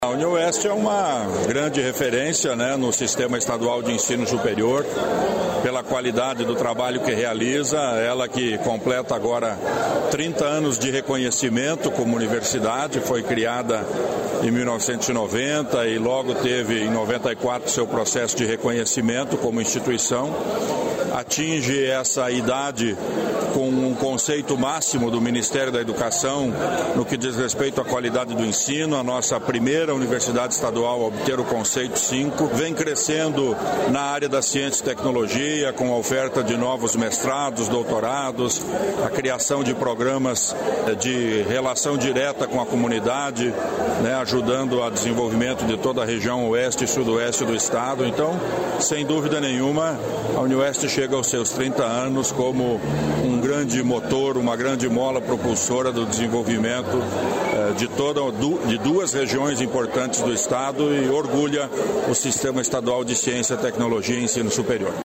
Sonora do secretário da Ciência, Tecnologia e Ensino Superior do Paraná, Aldo Bona, sobre os 30 anos da Unioeste